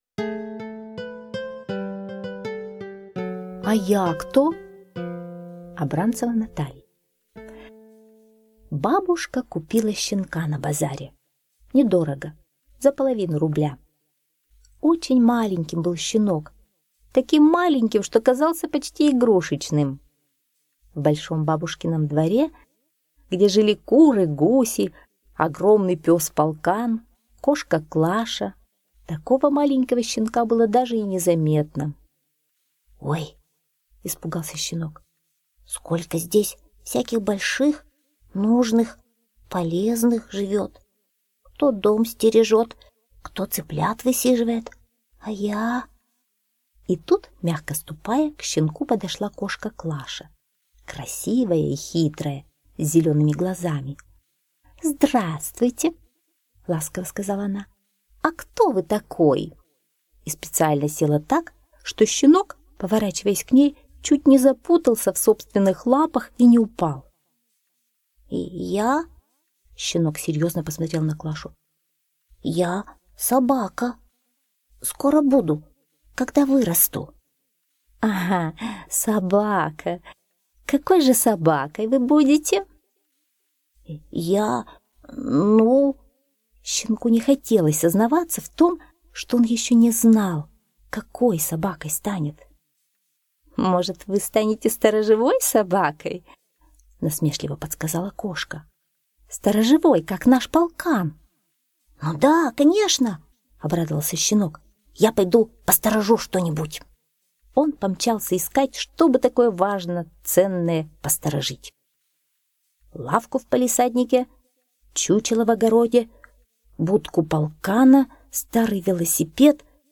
Аудиосказка «А я кто?»
Расказщица так хорошо рассказала<33